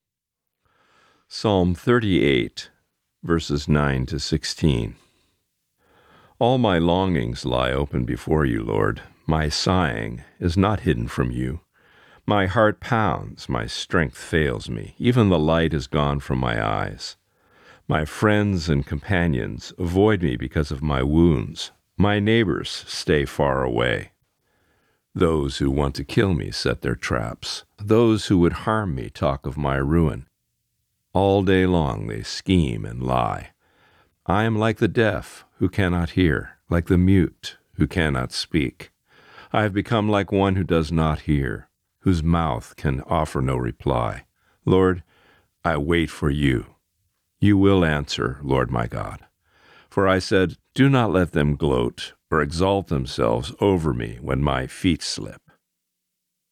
Reading: Psalm 38:9-16